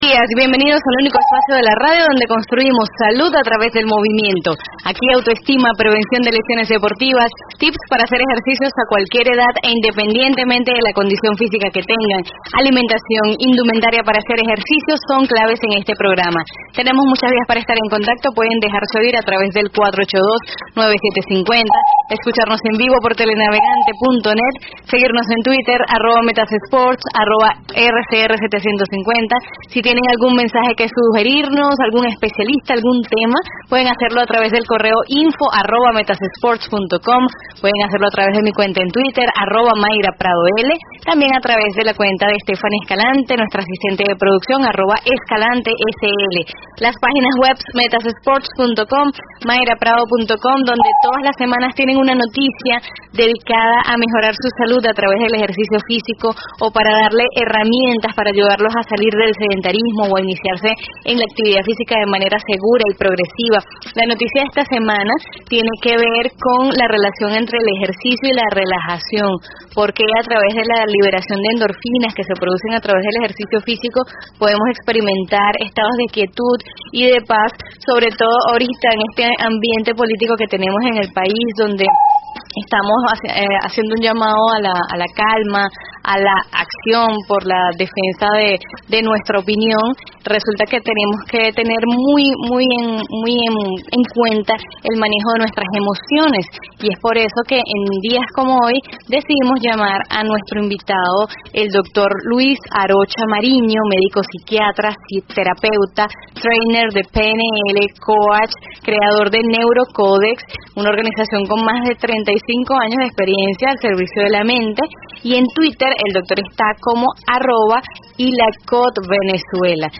¿Cuáles son las Claves para el Manejo de nuestras Emociones en el Ambiente Político Actual? (Entrevista en Radio)